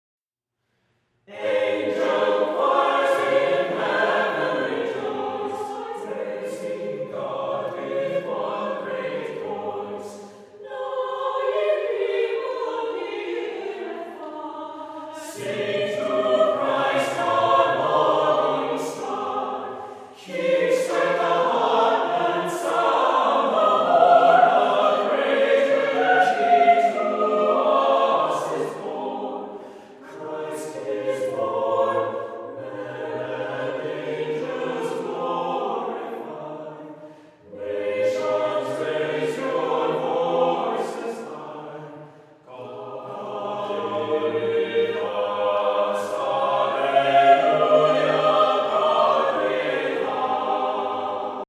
Tonality: A flat major